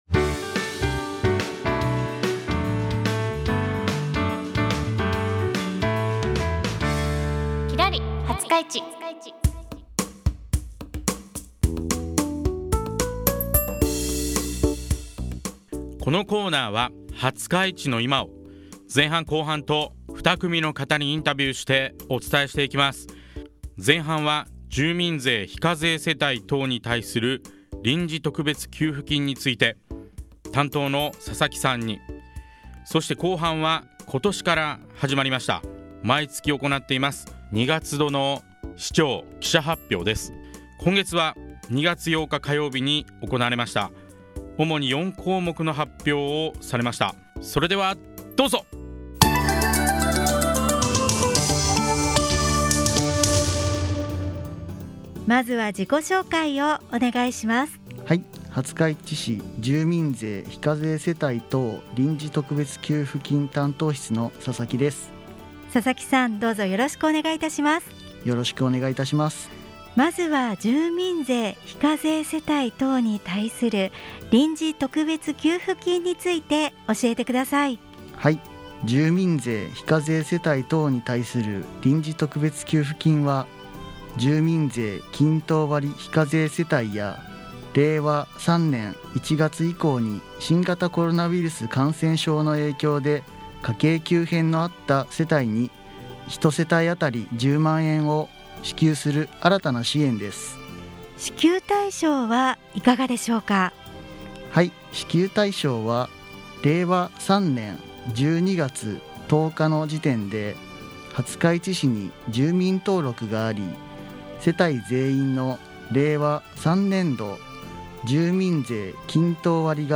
「きらり☆はつかいち」 廿日市の〝今〟をきらりと輝く2組の方にインタビュー！